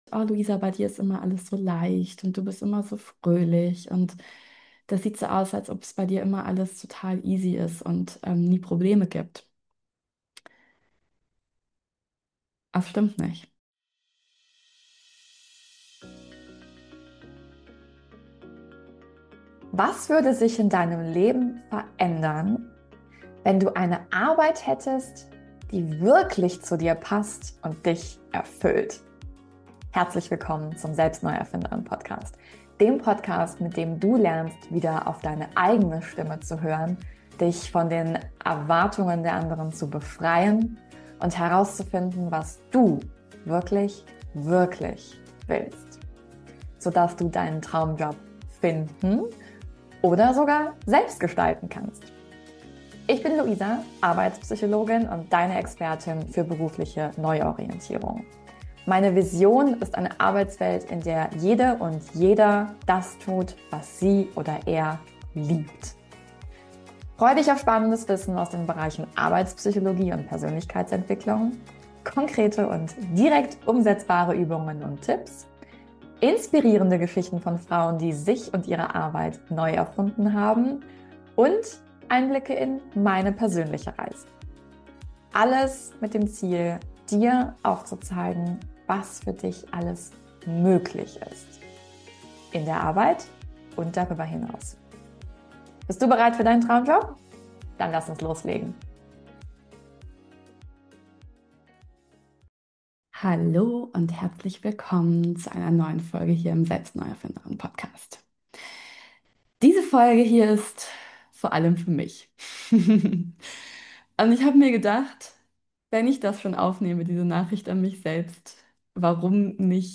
In dieser Folge nehme ich Dich mit in einen persönlichen Prozess von mir vor ein paar Wochen. Einfach aus dem Moment heraus aufgenommen.